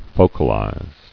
[fo·cal·ize]